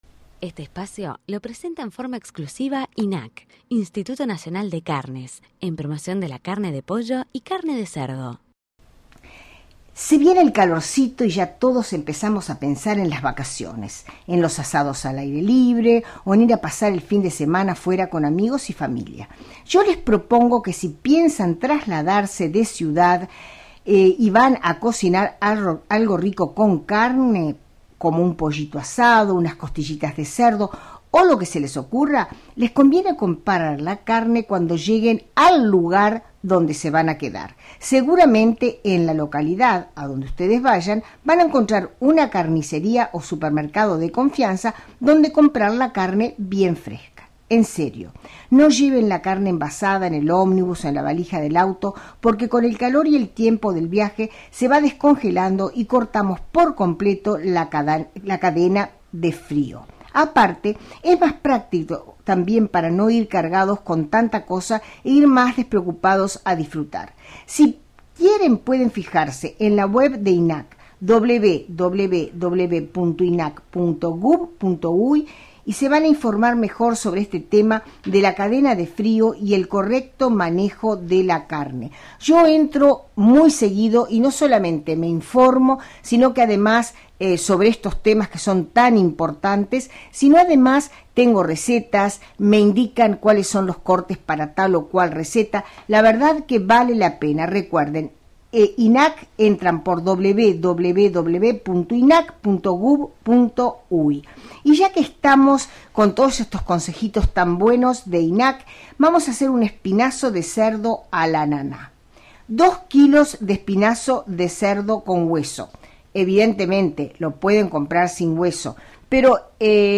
programa de radio. mp3. 5:49